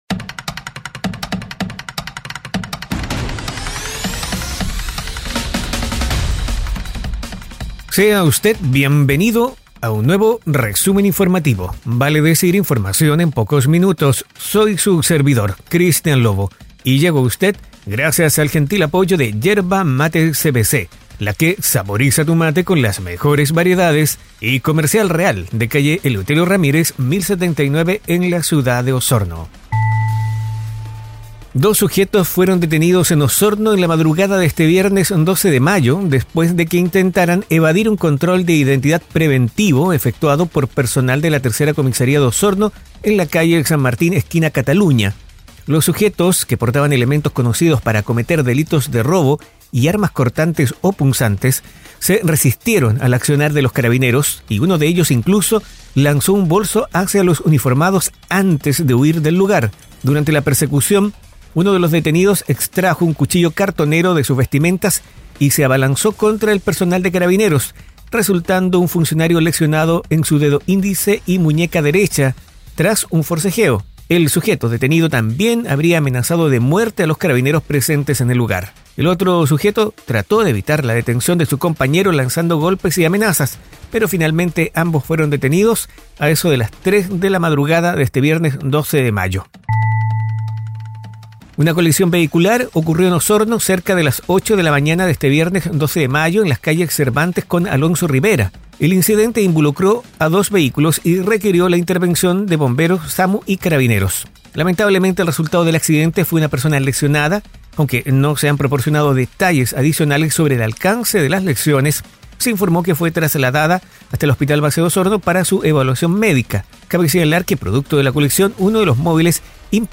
🐺Resumen informativo es un audio podcast con una decena de informaciones en pocos minutos, enfocadas en la Región de Los Lagos